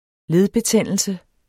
Udtale [ ˈleð- ]